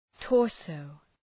Προφορά
{‘tɔ:rsəʋ}